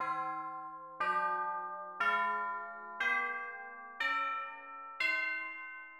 Slendro approximated in Western notation.[1]
Slendro-djawar scale in comparison with whole tone scale on C[2] Play both or Play equal-tempered scale.
Slendro_on_C.mid.mp3